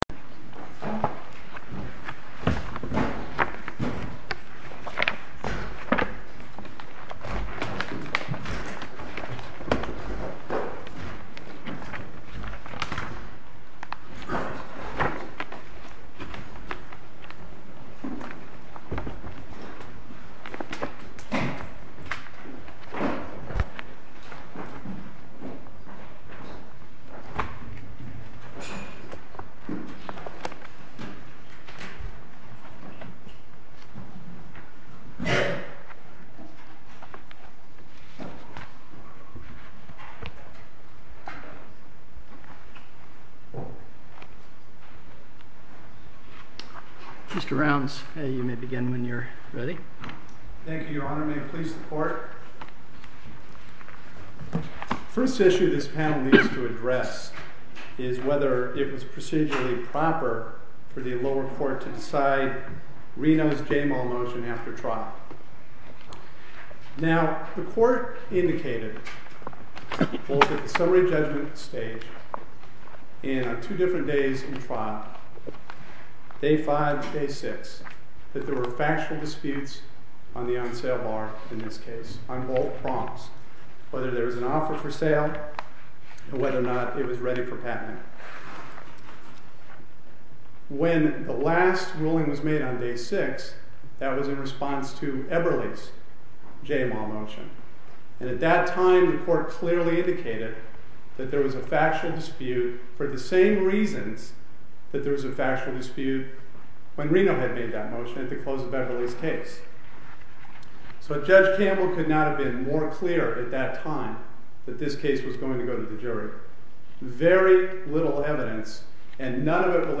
Oral argument audio posted: Eberle Design v Reno A&E (mp3) Appeal Number: 2006-1236 To listen to more oral argument recordings, follow this link: Listen To Oral Arguments.